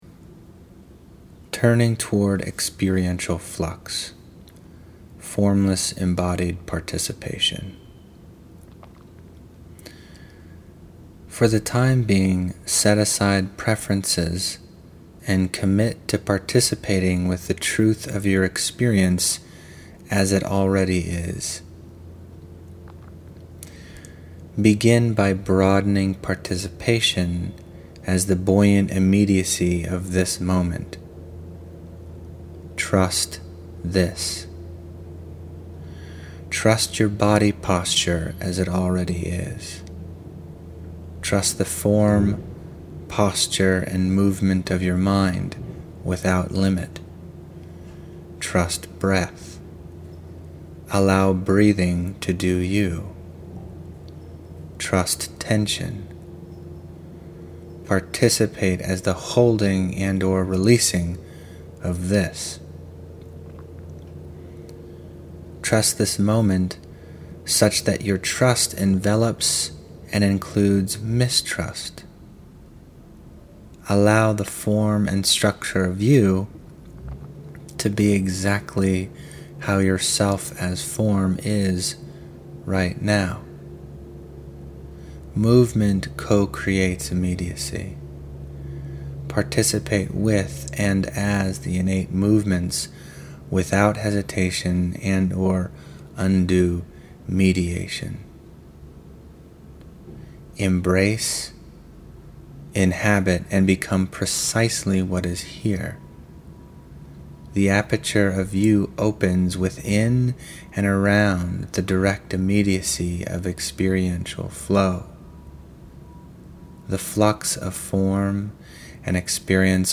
Narrated exercises from Chapter Seven, The Paradox of Completeness: